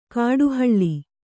♪ kāḍu haḷḷi